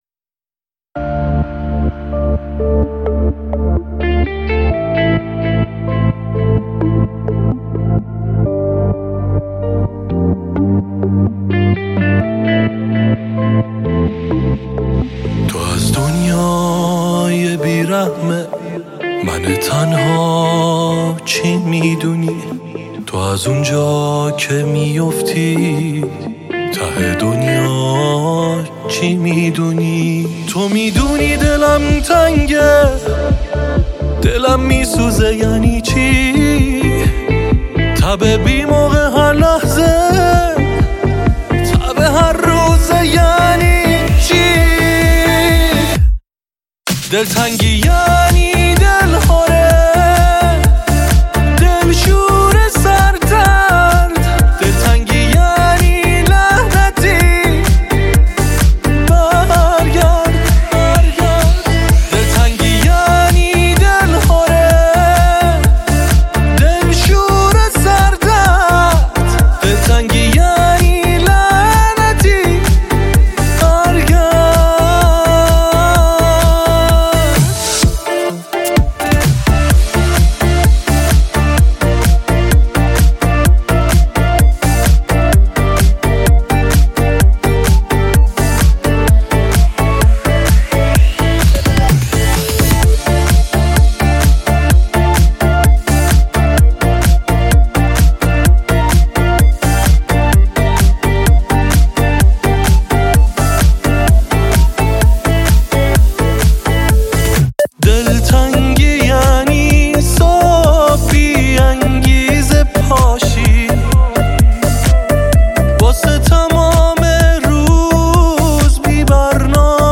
غمگین
پاپ